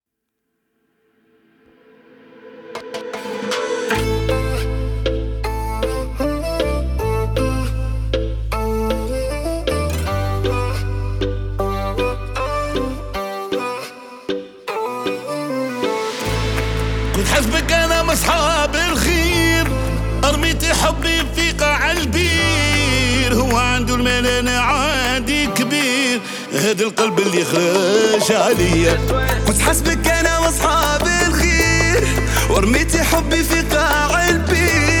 Maghreb Rai Arabic